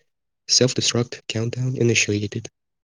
self-destruct-countdown.wav